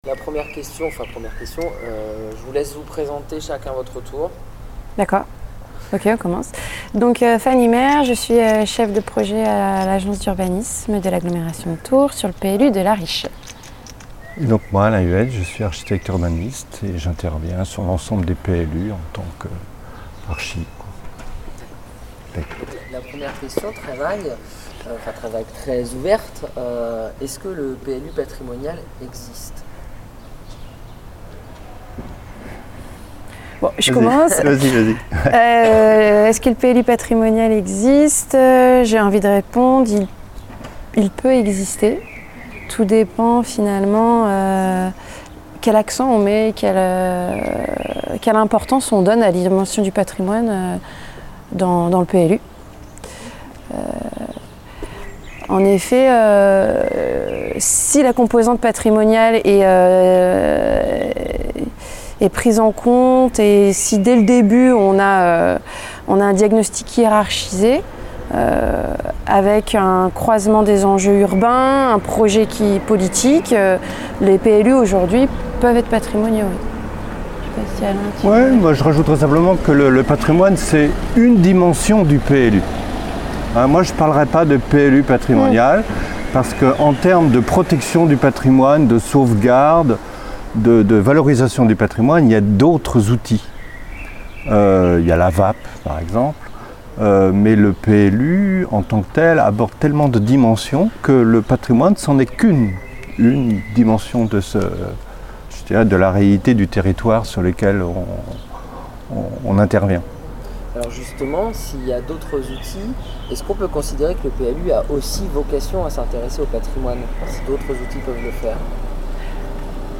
Entretien réalisé à La Riche le 21 juin 2016. À noter : Cet entretien est un matériau de recherche brut, qui a nourri les réflexions des chercheurs dans le cadre du projet PLU PATRIMONIAL.